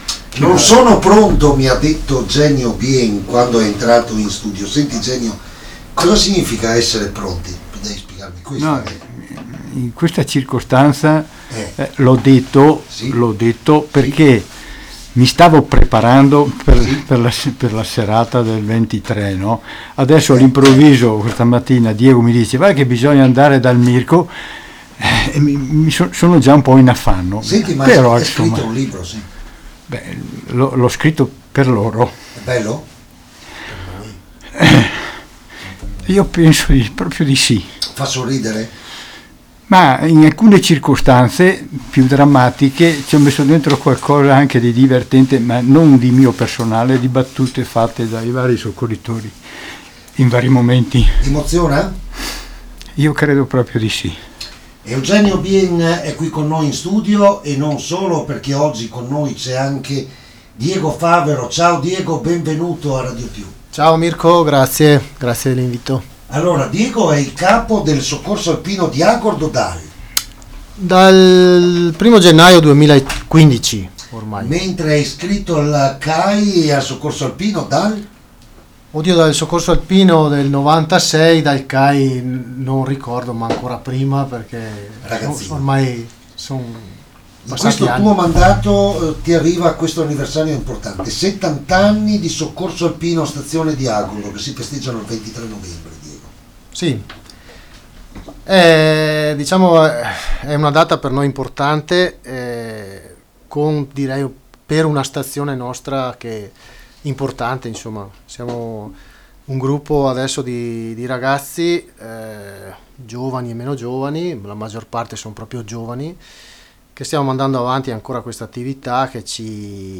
DALLA SEDE DEL SOCCORSO ALPINO DI AGORDO